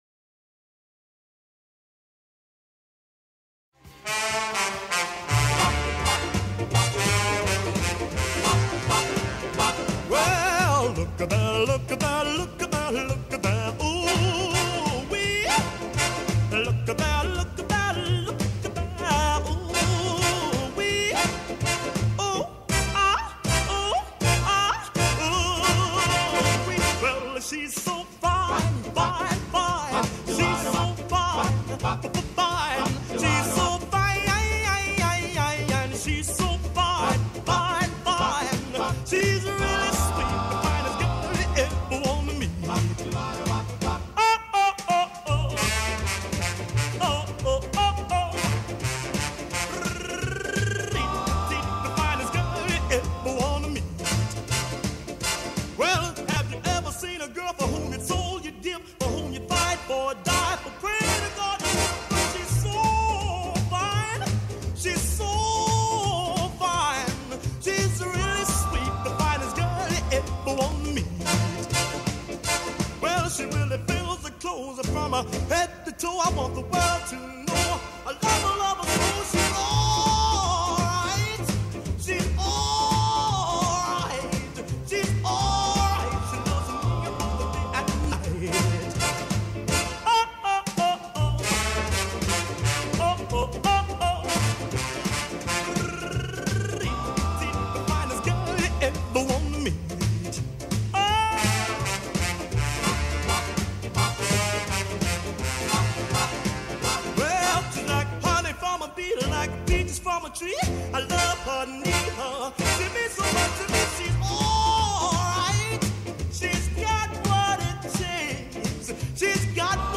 Allá por el año 57 un cantante de soul